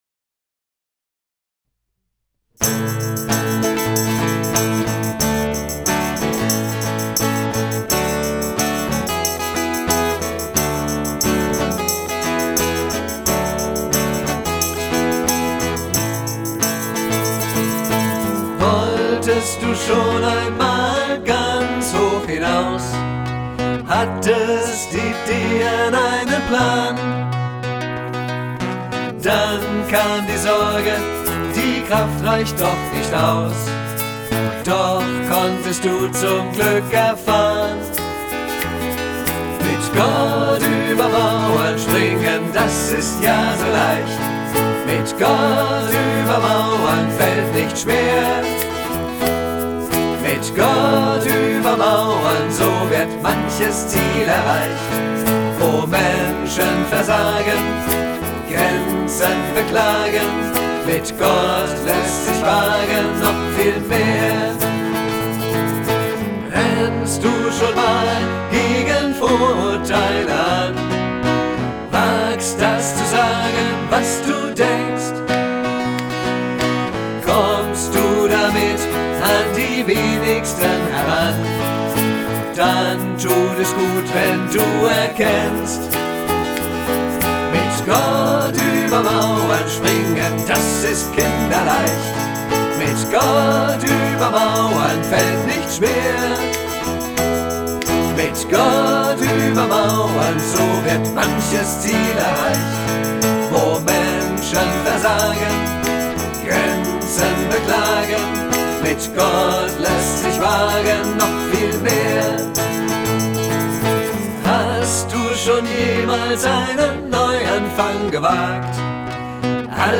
Audio studio version (3:44)Herunterladen